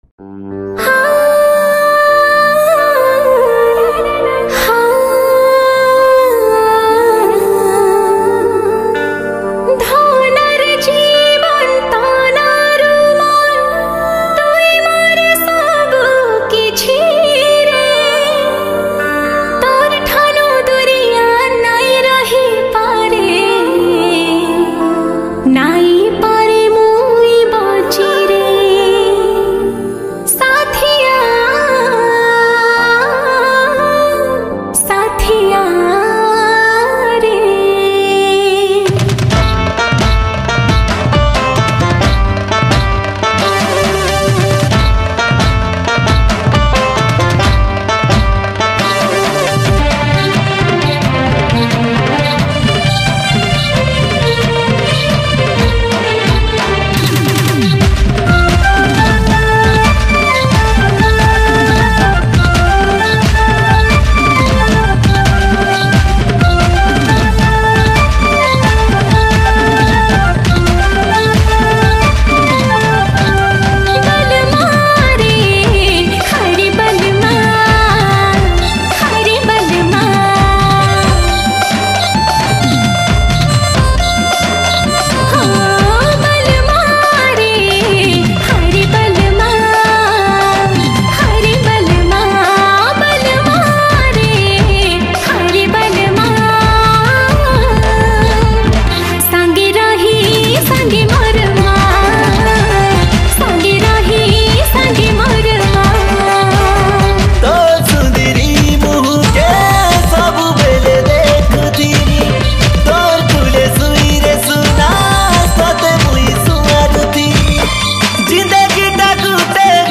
New Sambalpuri Song